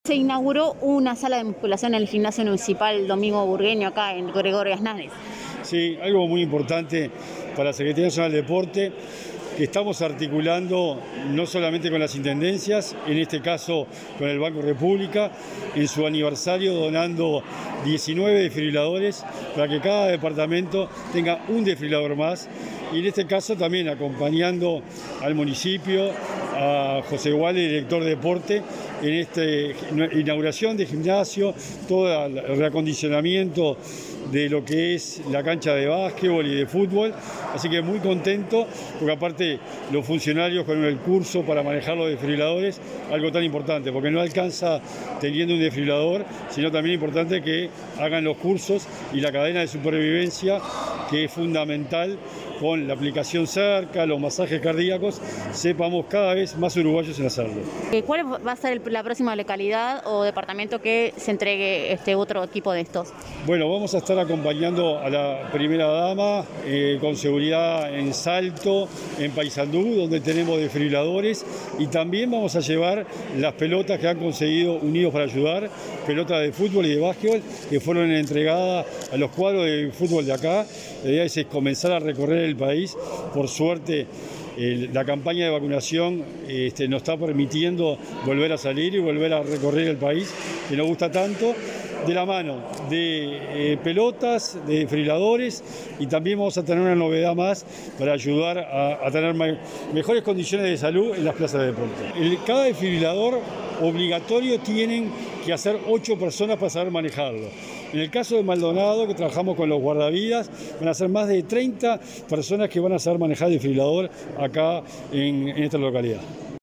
Declaraciones del secretario del Deporte, Sebastián Bauzá